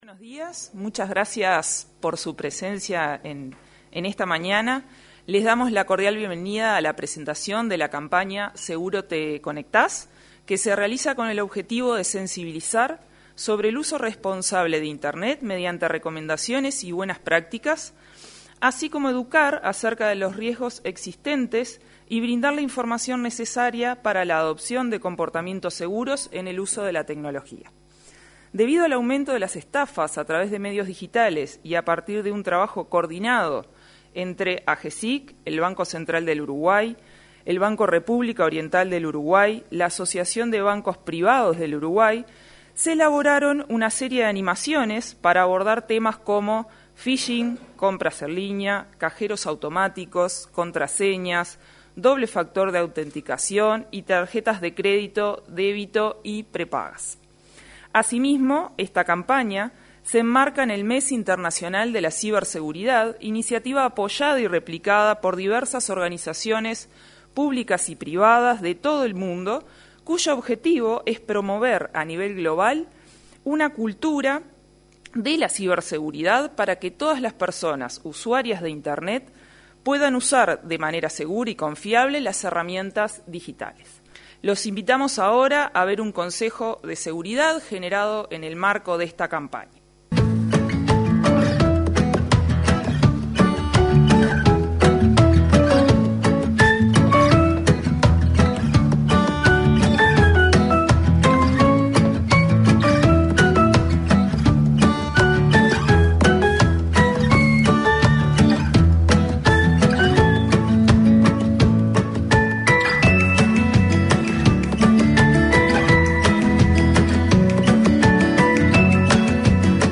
Presentación de la campaña “Seguro te conectas”